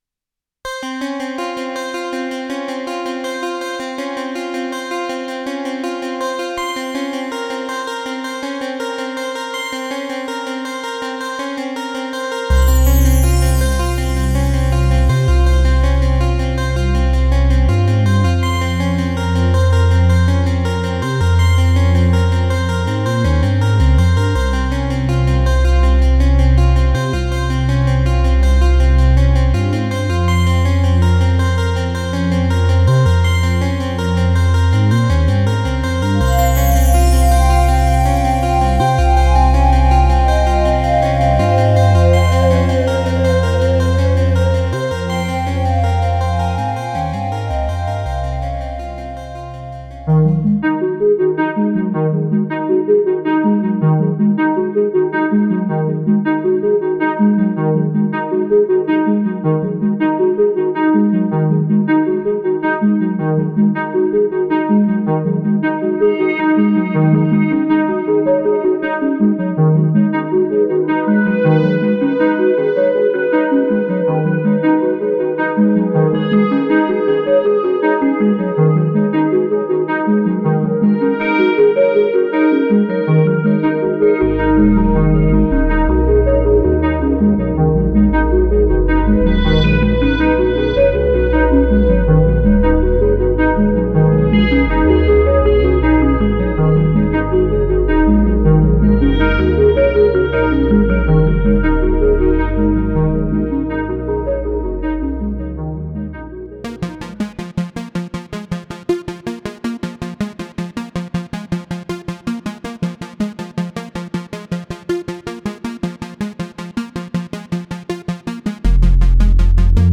AMBIENT IN ITS MOST BEAUTIFUL EXPRESSION: DRIFT AT ITS MOST EXPRESSIVE, ORGANIC AND LIVING
AMBIENT-DRIFT-DEMO-MP3.mp3